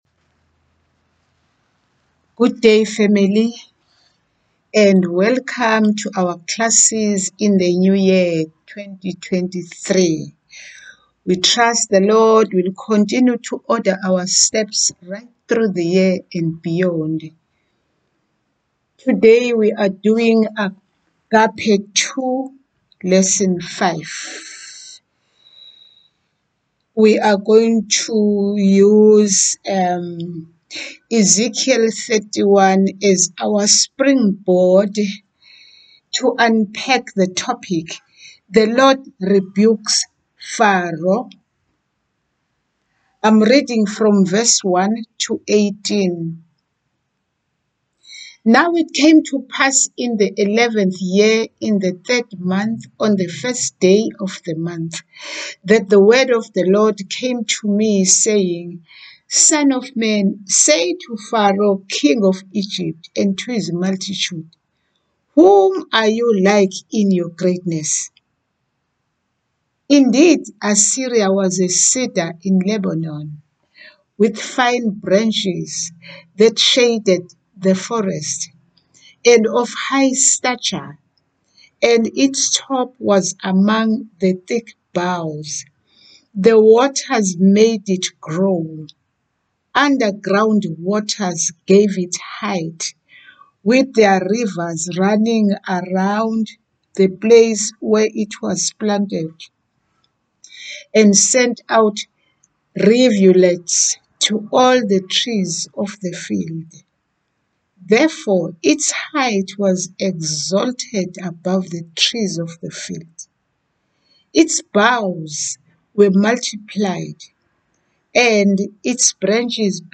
LISTEN-TO-AGAPE-2-LESSON-5-MP3.mp3